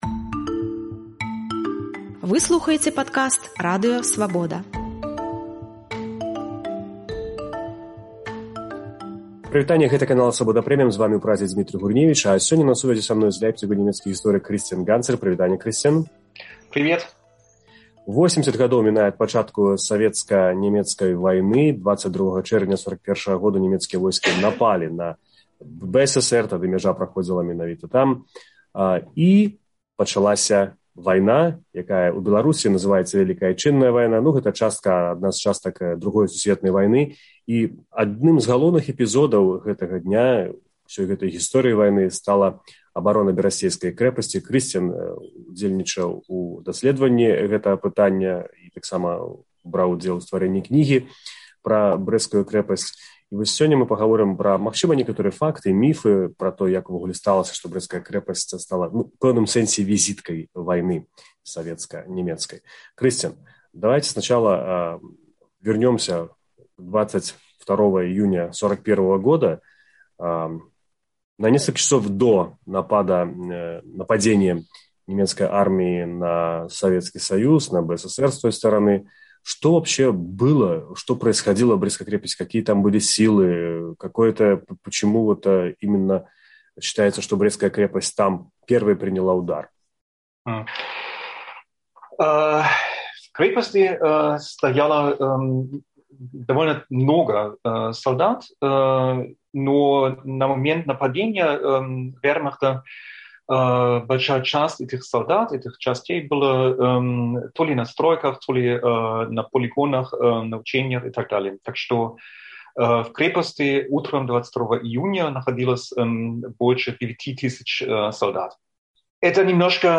У інтэрвію "Свабодзе" ён расказвае пра новыя факты і абвяргае міты савецкай гістарыяграфіі, якія зрабілі з крэпасьці "візытку" нямецка-савецкай вайны.